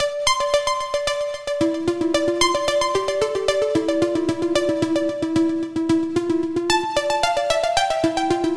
描述：陷阱钢琴
Tag: 112 bpm Trap Loops Piano Loops 1.44 MB wav Key : Unknown